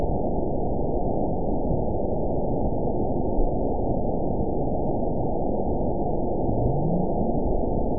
event 920366 date 03/18/24 time 22:26:26 GMT (1 year, 3 months ago) score 9.62 location TSS-AB01 detected by nrw target species NRW annotations +NRW Spectrogram: Frequency (kHz) vs. Time (s) audio not available .wav